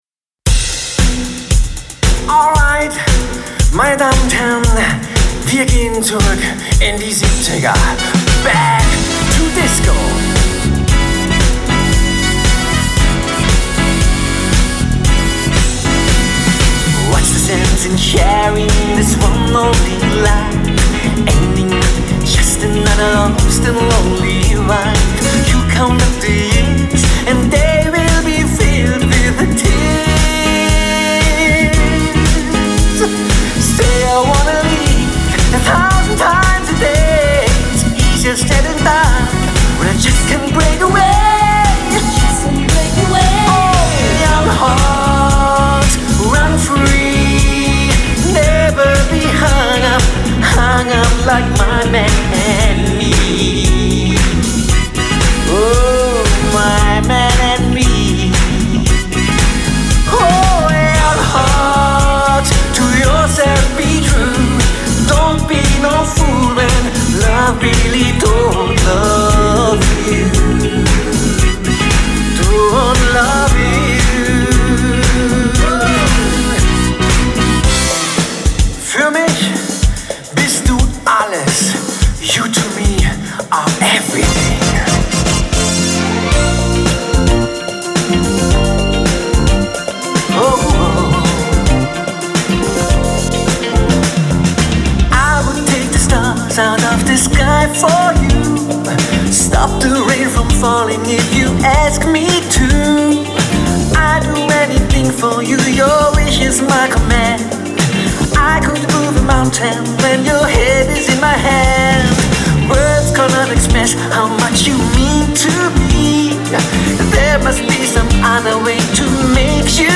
Genre: Event Entertainment